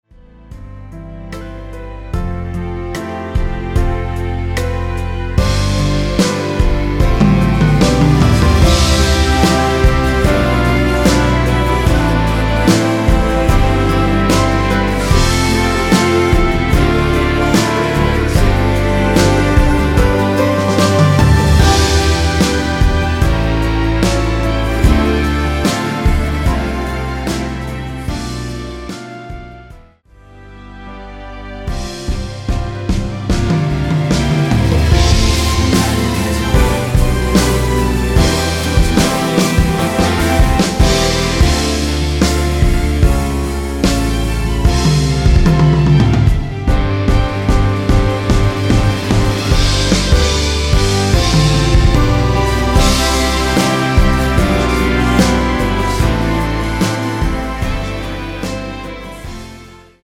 코러스 MR입니다.
원키 코러스 포함된 MR입니다.(미리듣기 확인)
앞부분30초, 뒷부분30초씩 편집해서 올려 드리고 있습니다.